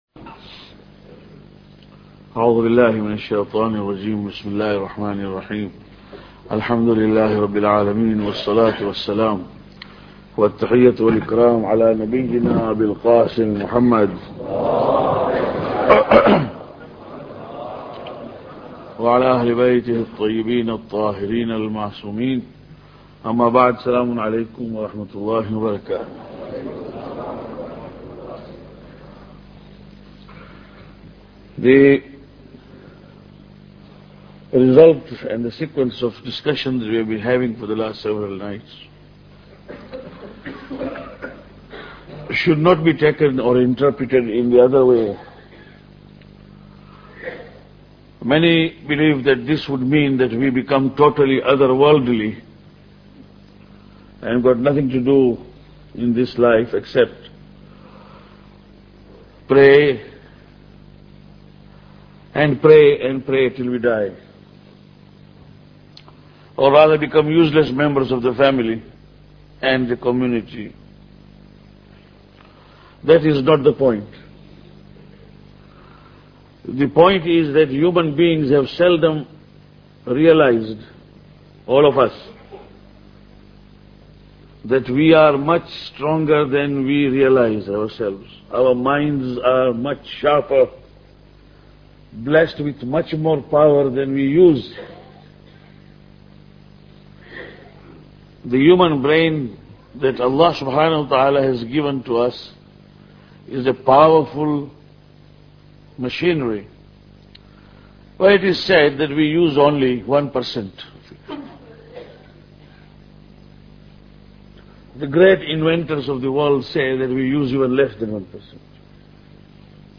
Lecture 6